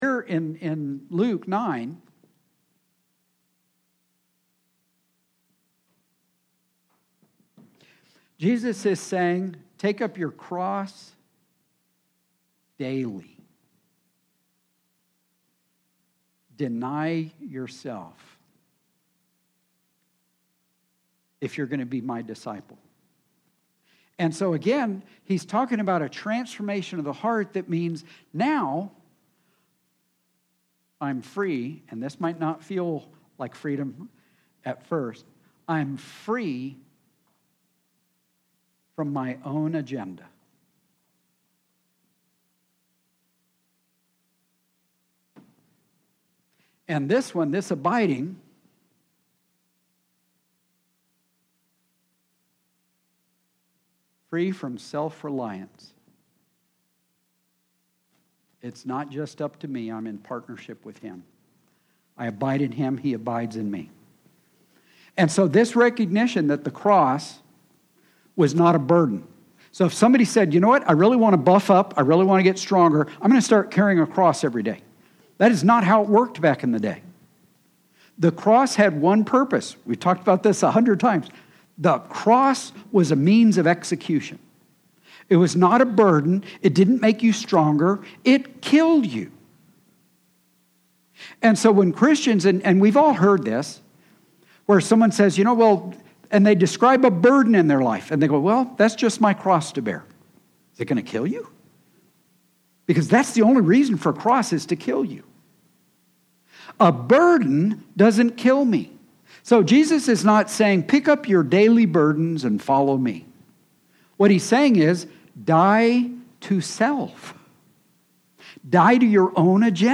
Sorry – we missed the beginning of the sermon today.